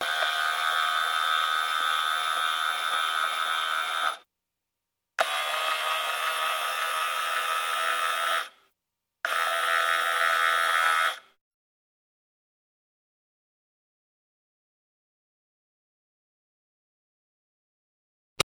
Car Power Mirror Sound
transport
Car Power Mirror